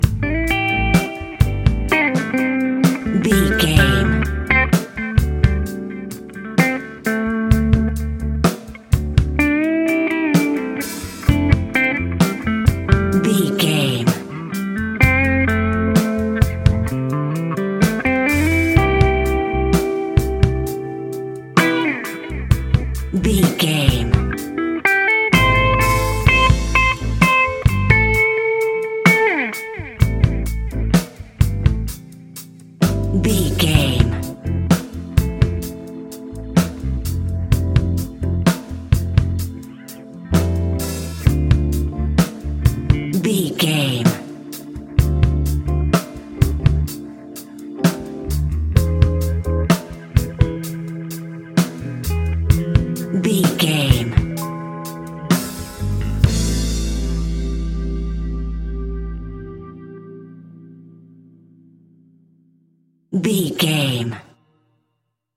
Epic / Action
Fast paced
In-crescendo
Uplifting
Ionian/Major
A♭
hip hop